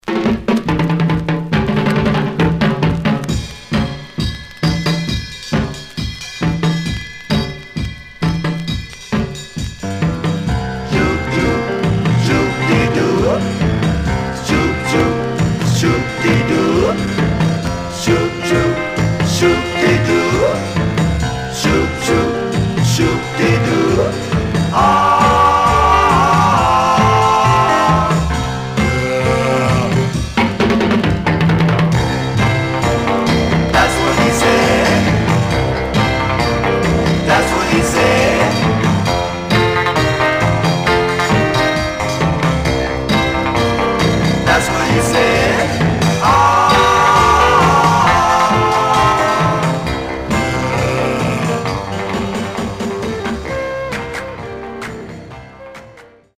Male Black Groups